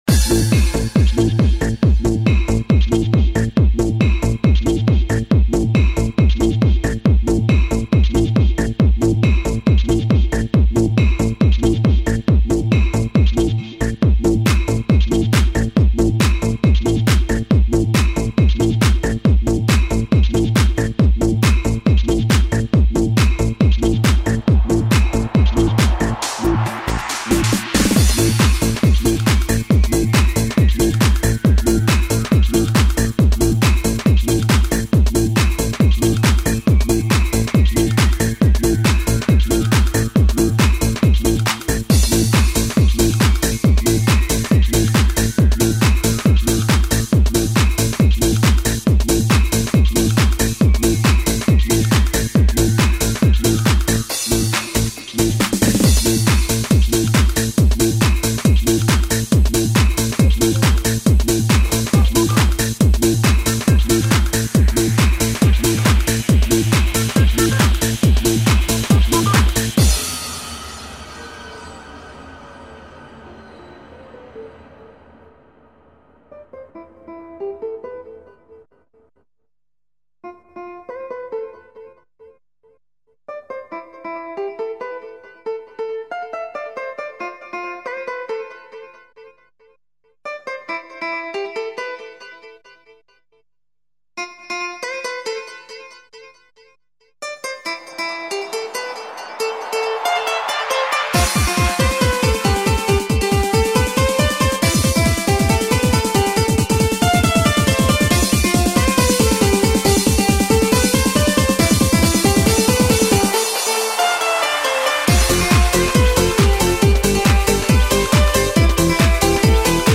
Энергия - эмоции - чувства - движение...
Жанр:Electronic